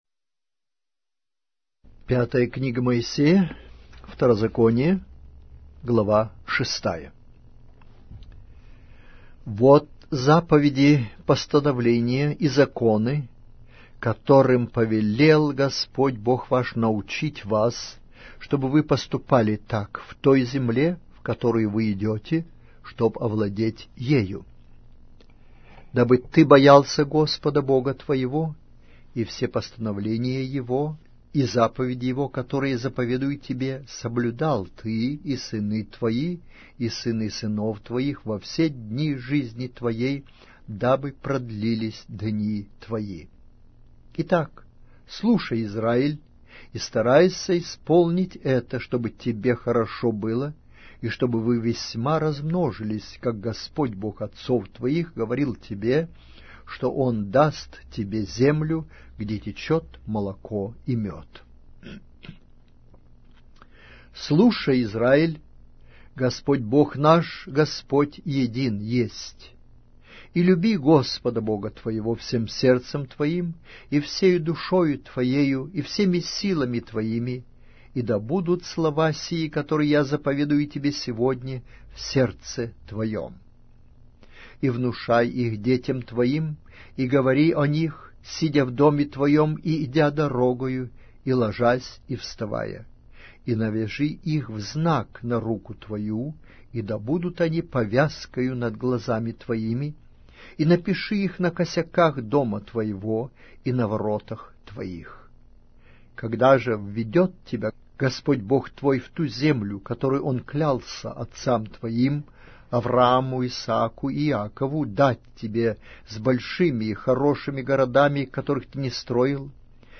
Аудиокнига: Книга 5-я Моисея. Второзаконие